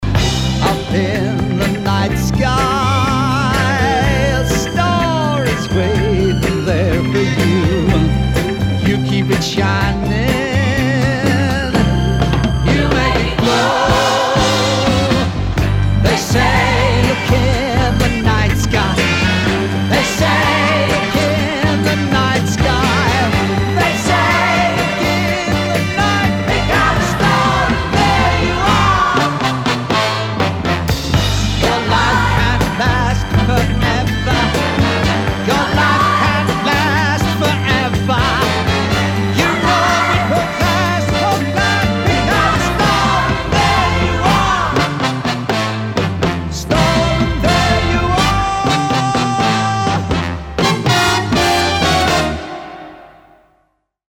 Lavishly orchestrated